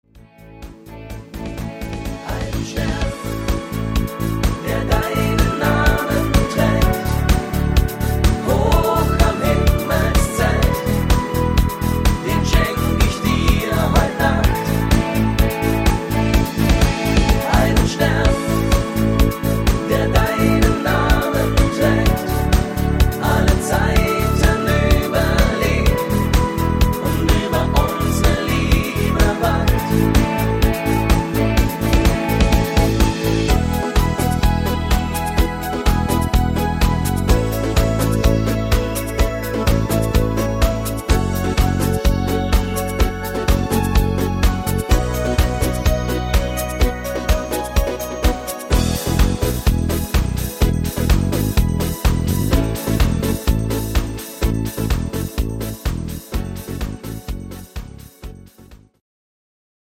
9369  Info   Discofox-Hitmix (gut zu singende Tonarten